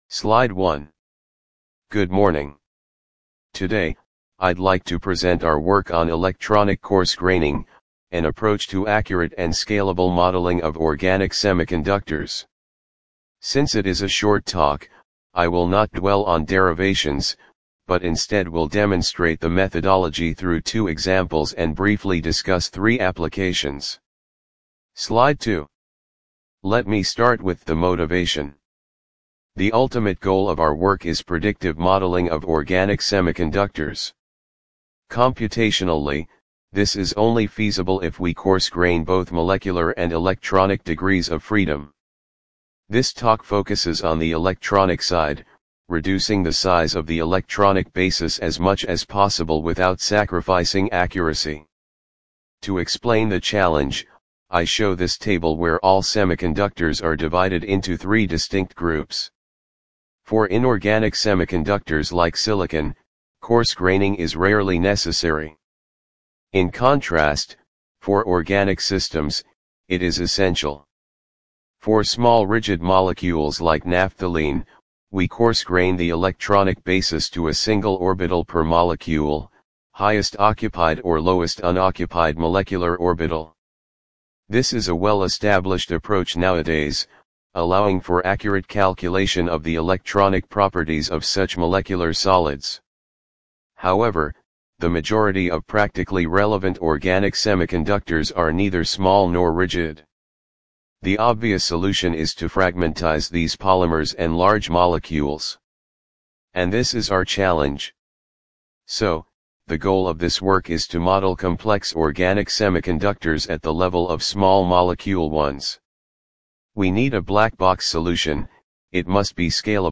TTS-audio